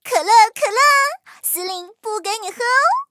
M4谢尔曼司令部语音1.OGG